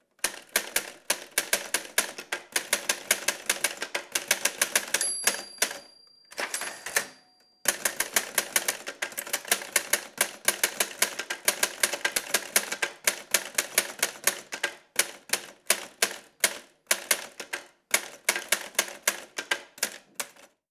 Teclear en una máquina de escribir
máquina de escribir
Sonidos: Oficina